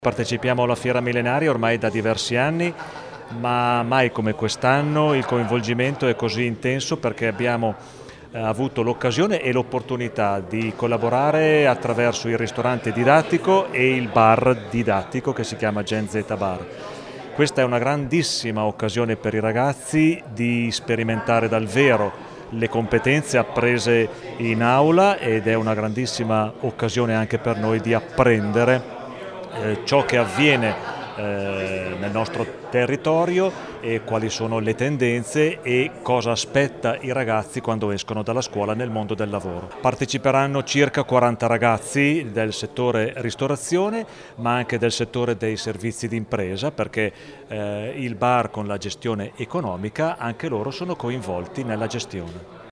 Le interviste
Ecco ai nostri microfoni le dichiarazioni a seguito della conferenza stampa dedicata all’evento: